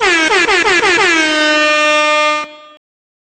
022_AIRHORN.wav